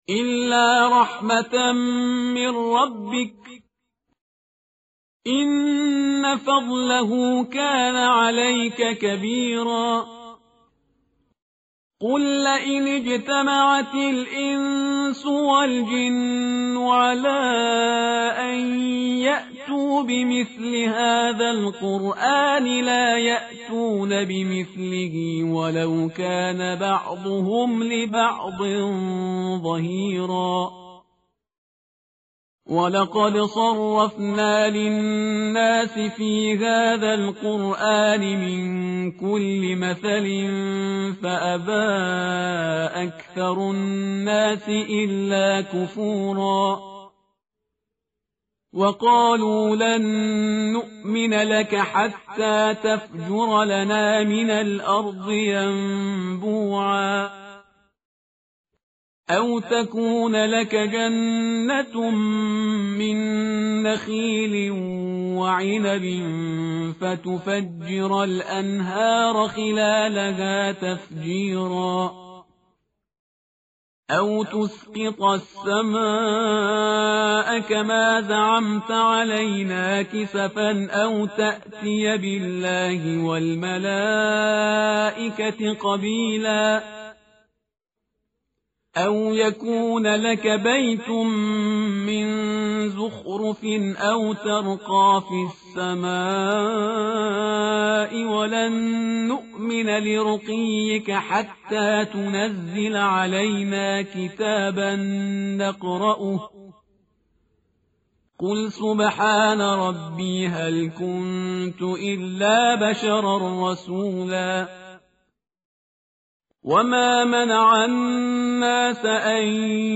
متن قرآن همراه باتلاوت قرآن و ترجمه
tartil_parhizgar_page_291.mp3